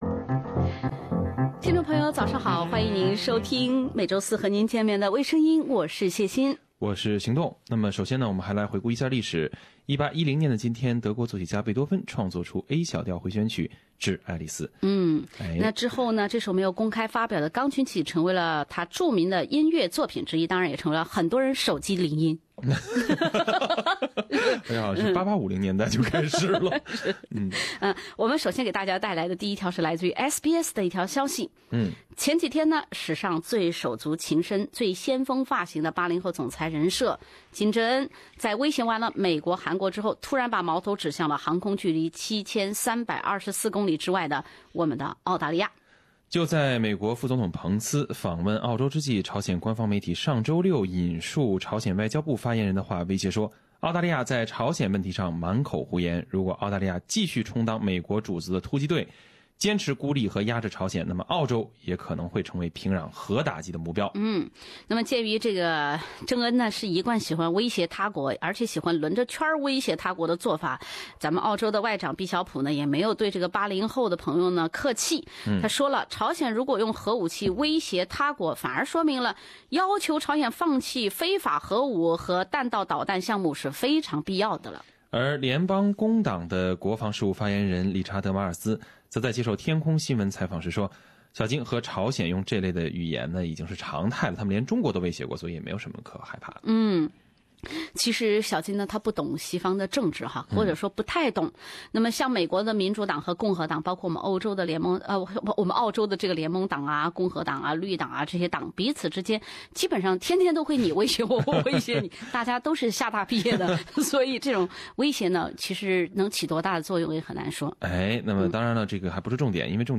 另类轻松的播报方式，深入浅出的辛辣点评；包罗万象的最新资讯；倾听全球微声音